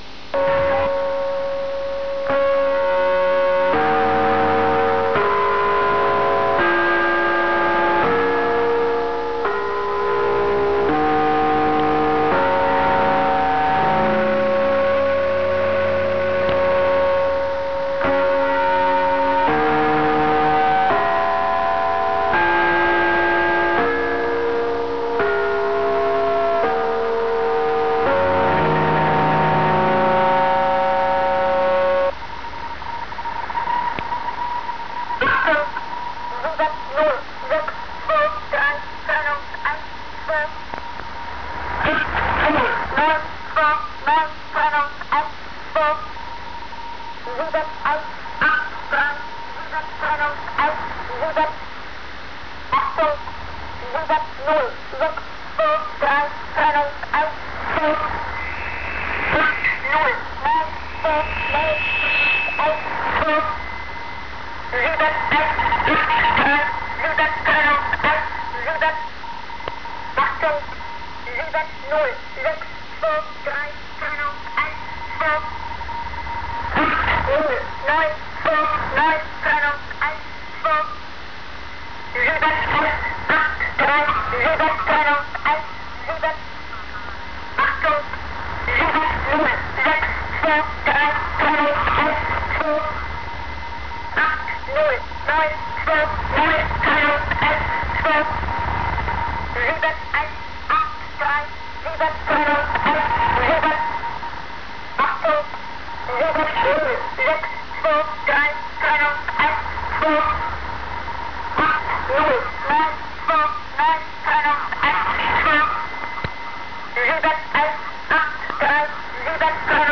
This station opened with a very unusual recording of what sound somewhat like the bells used by a lighthouse to wars ships of the coast during foggy weather. The tune consisted of eight notes "Fa Me Ray Do Ray Me Fa So"
After the eerie musical introduction, a woman would practiclaly shout (in German) the five digit headers for the messages to follow, each followed by a trennung (slash) and the number of groups in that message (two digits). This was followed by "achtung" and the first message.